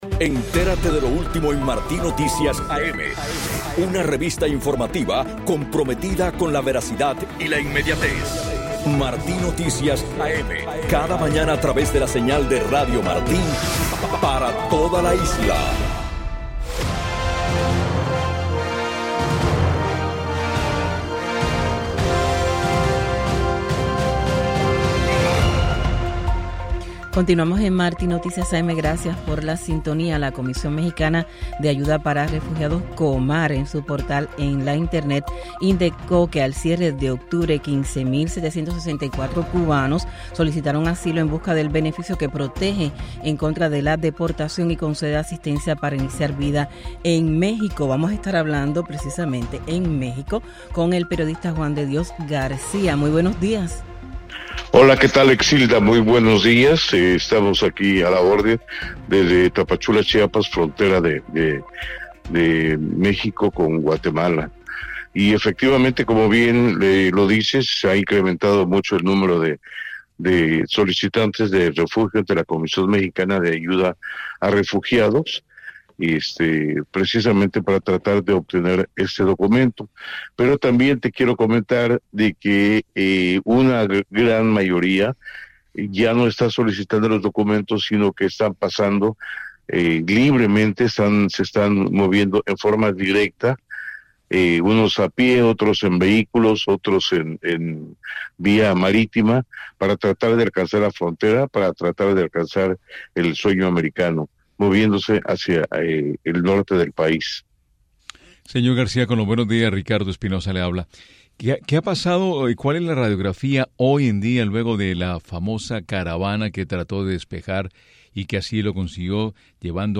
En entrevista con la revista informativa Martí Noticias AM, el periodista dijo que “una gran mayoría de estos migrantes no están solicitando documentos para el refugio en el territorio, sino que están en tránsito con el objetivo de llegar a la frontera, moviéndose hacia el norte del país”.